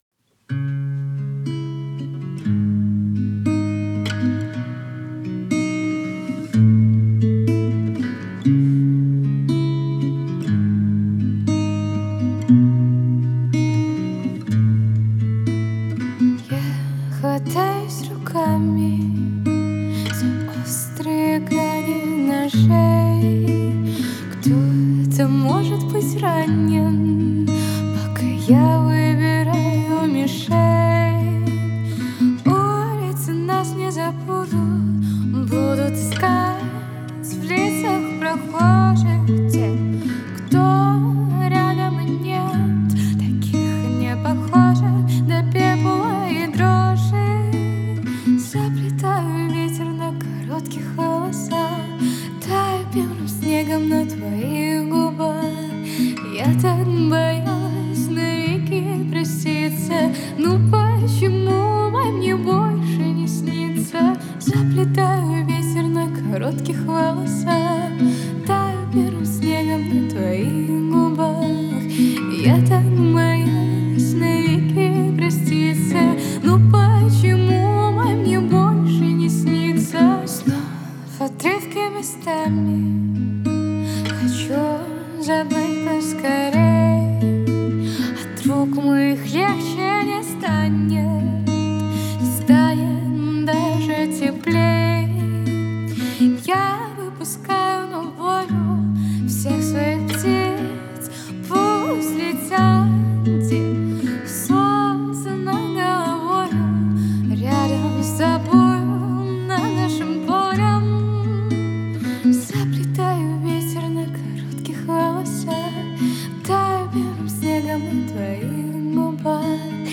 Грустняк